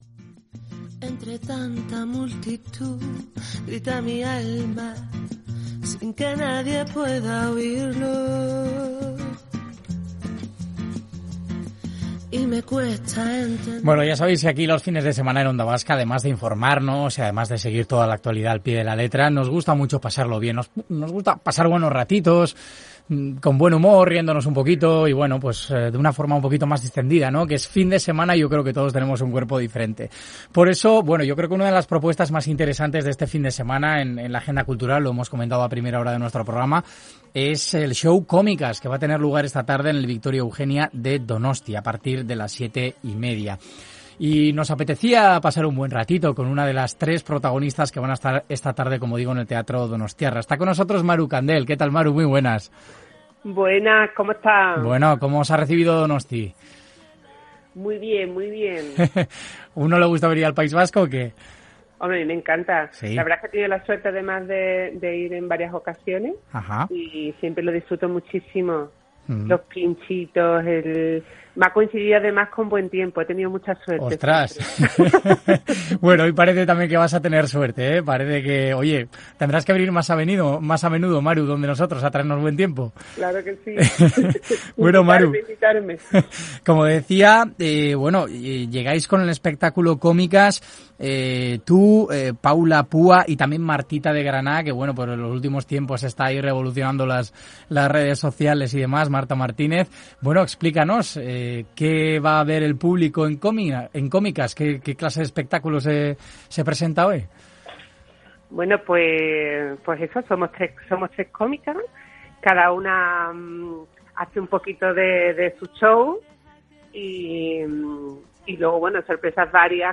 Una charla sincera y cercana que queremos compartir con vosotros.